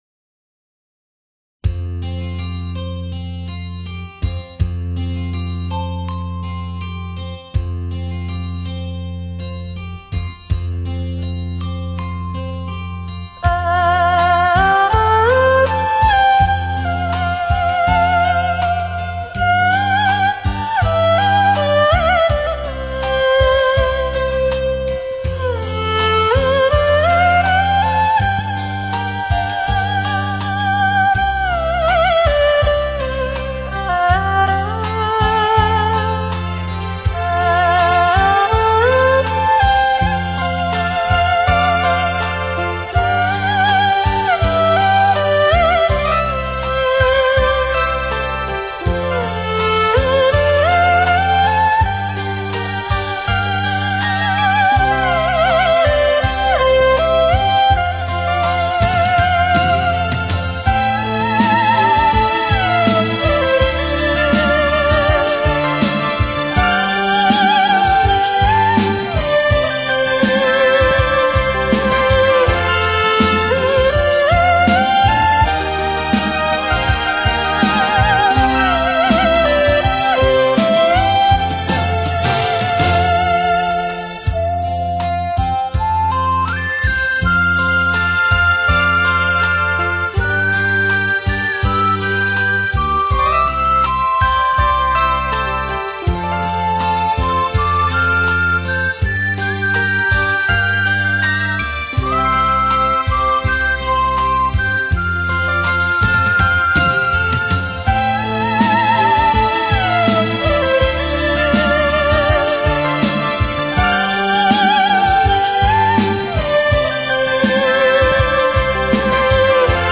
经典旋律  韵味浓郁  细腻极品
二胡是我国优秀民族传统乐器中最有特色的乐器。
它的音色深厚、甜润、纯正而雅致，能给人以悦耳动听
刚劲而秀美之感，接近人声又略带伤惋的艺术魄力。